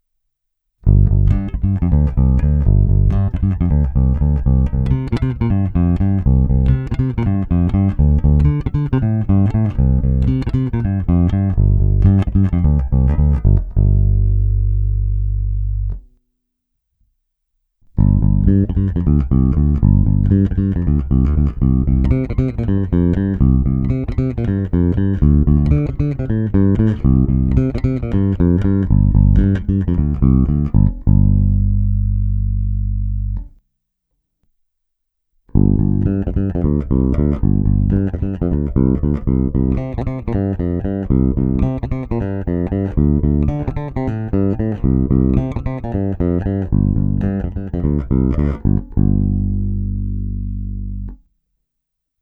Zvuk má výrazný moderní charakter, nejspíše i díky dvoucívkovým snímačů mi zvuk přišel nepatrně zastřenější, ale není problém lehce přitlačit na korekcích výšek, dodat tam tak průzračnost a vzdušnost.
Není-li uvedeno jinak, následující nahrávky jsou provedeny rovnou do zvukové karty.